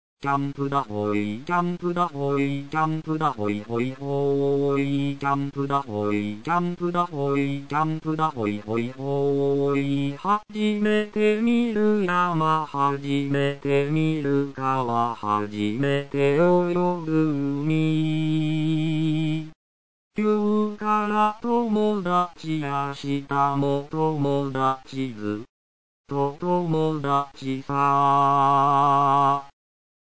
アカペラを聴く